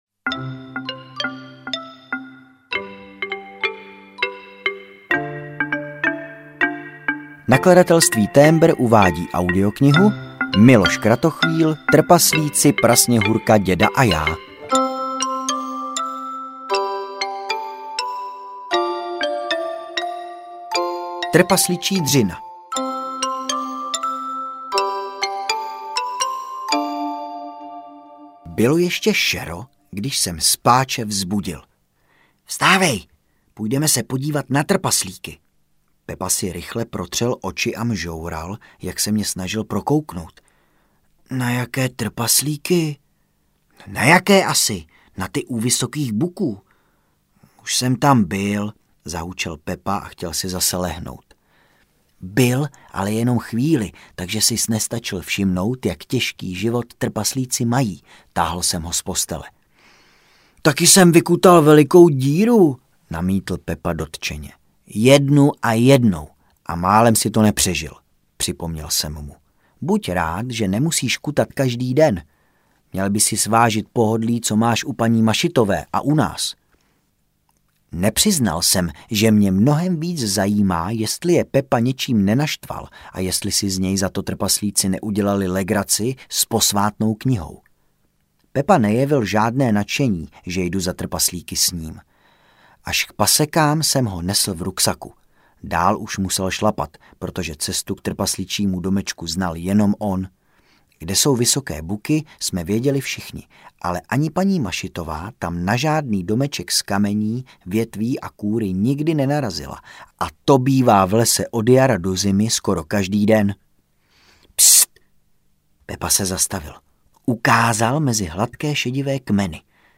Ukázka z knihy
trpaslici-prasnehurka-deda-a-ja-audiokniha